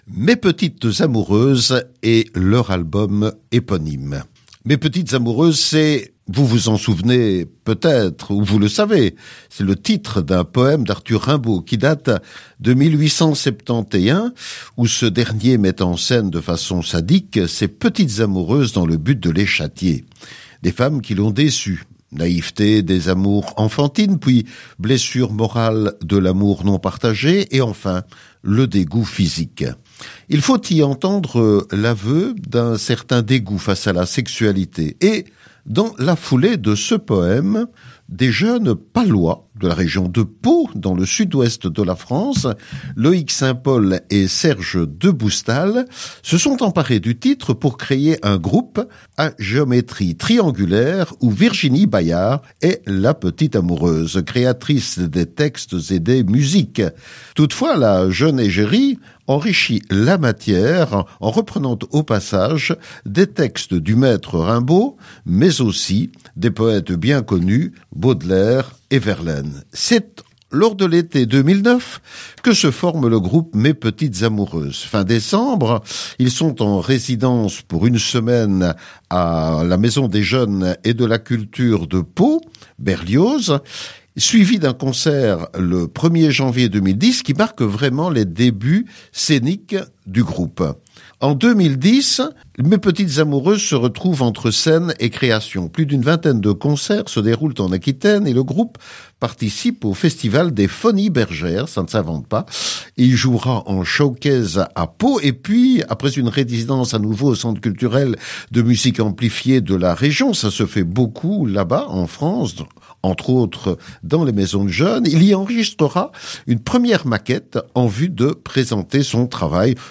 Composé de trois membres multi-instrumentistes